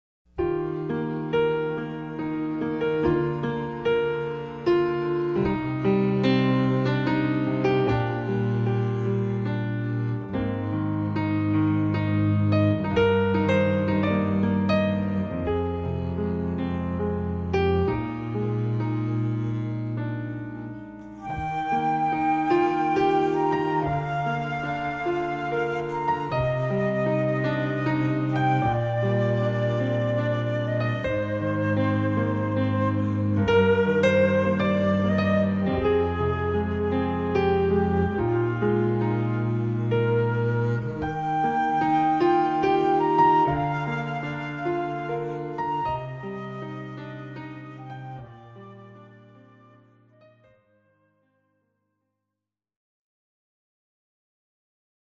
Classical Trio (Piano, String Bass, & Flute)